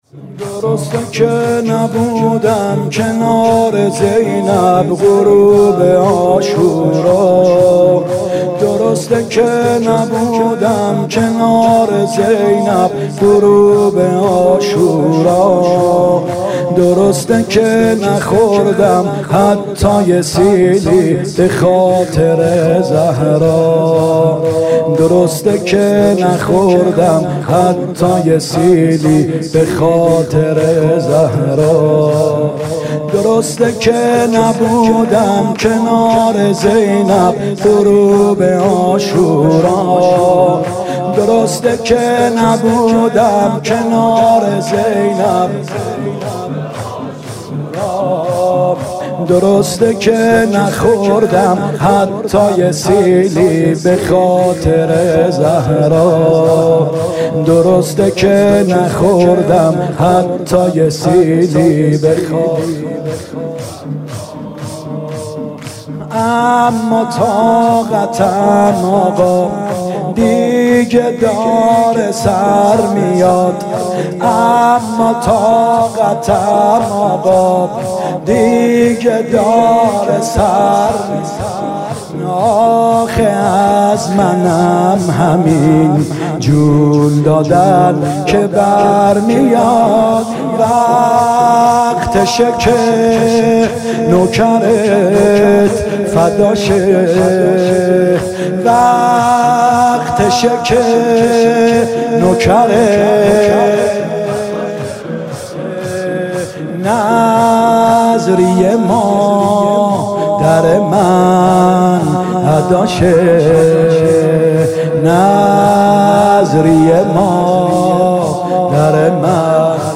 فاطمیه اول ( شب اول ) هیات یا مهدی (عج ) 1399
شور (درسته که نبودم کنار زینب غروب عاشورا)